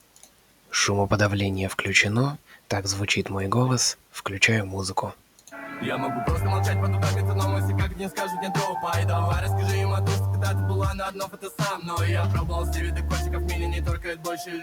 С_шумоподавлением.ogg